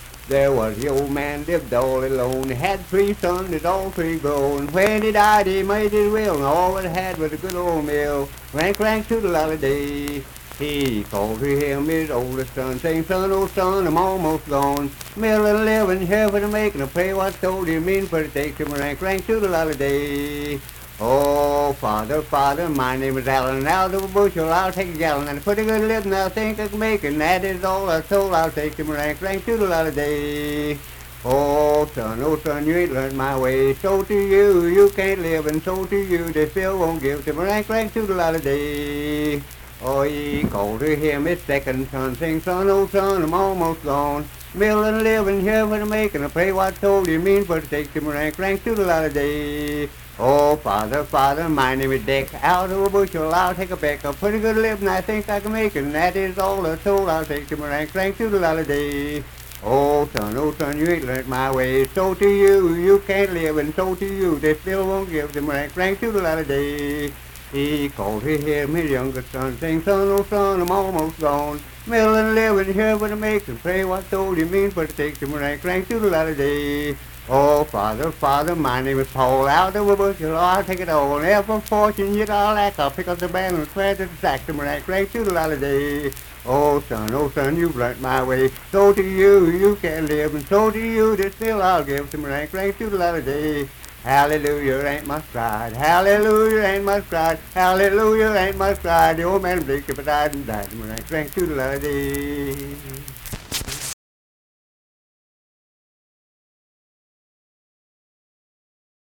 Unaccompanied vocal and banjo music
Verse-refrain 11(5w/R).
Voice (sung)